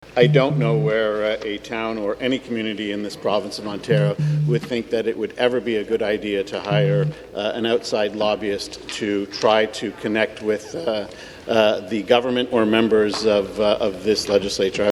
Government House Leader Paul Calandra: